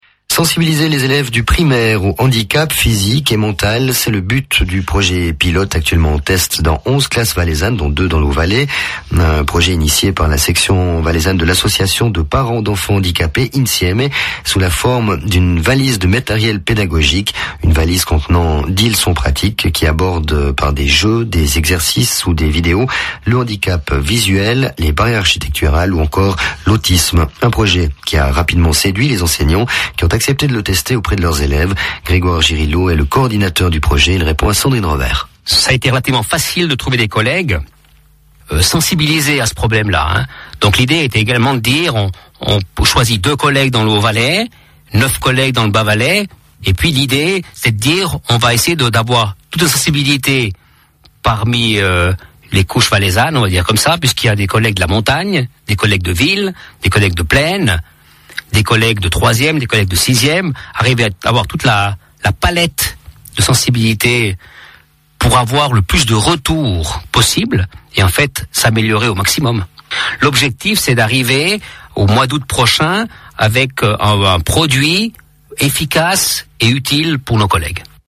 JOURNAL 12 H 30 DU 9 DECEMBRE 2009